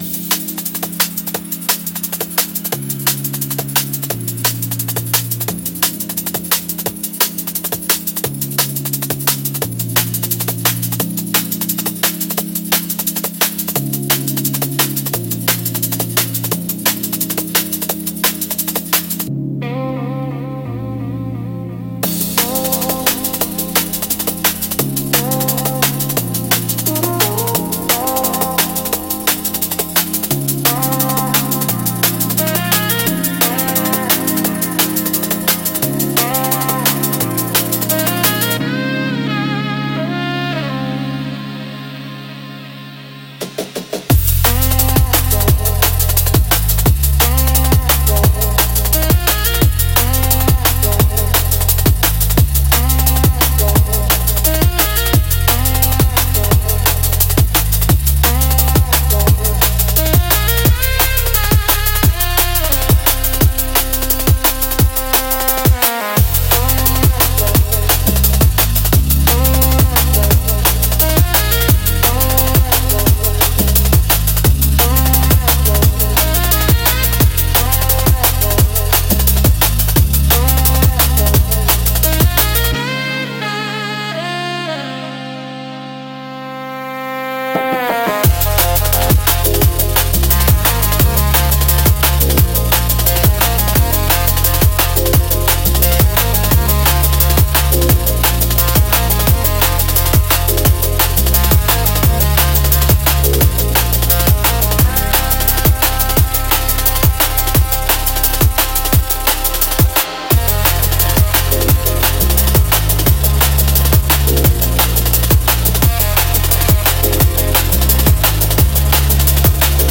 テンポの速さと複雑なリズムで、聴く人の集中力と興奮を引き上げる効果があります。エッジの効いたダイナミックなジャンルです。